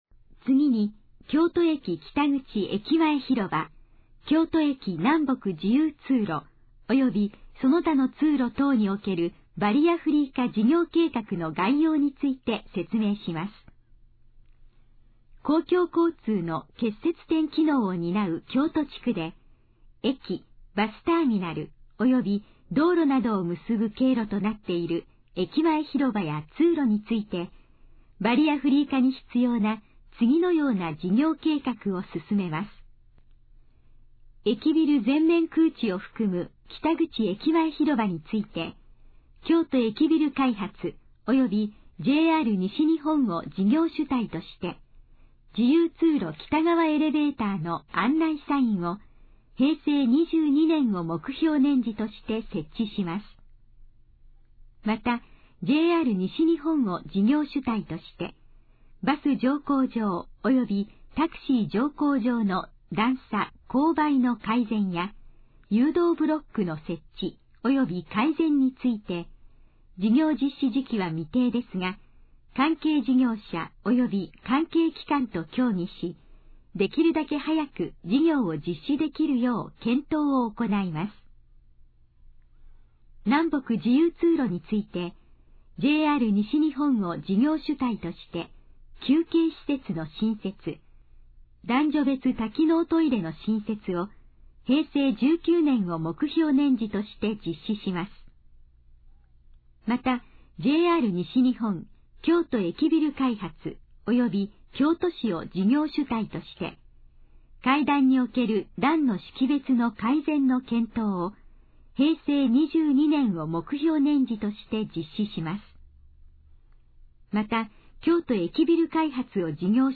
以下の項目の要約を音声で読み上げます。
ナレーション再生 約422KB